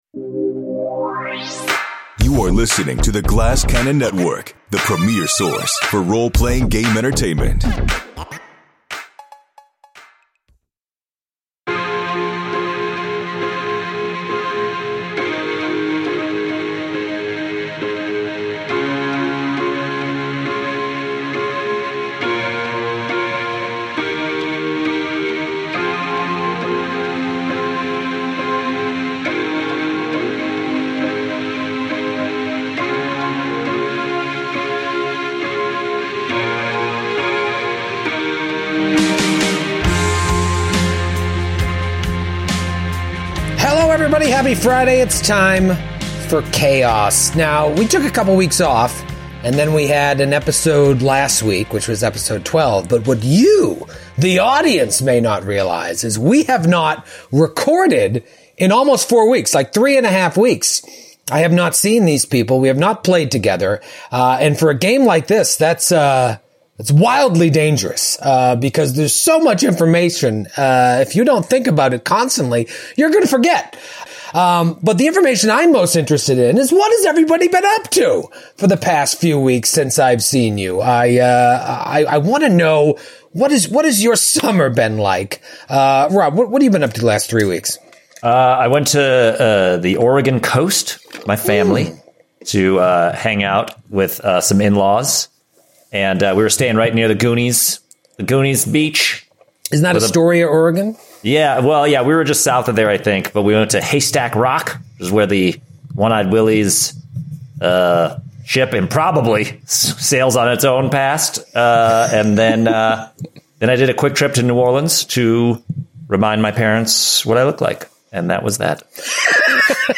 In the spirit of old-school tabletop role-playing games, a collection of five super-nerds engage in an officially licensed Actual Play podcast of Paizo’s Giantslayer Adventure Path!